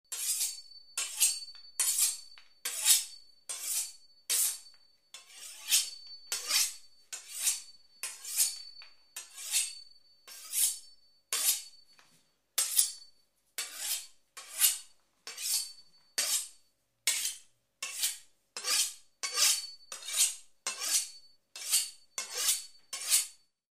Слушайте онлайн или скачивайте бесплатно резкие, металлические скрежеты и ритмичные движения точильного камня.
Большой мясной нож точат о мусат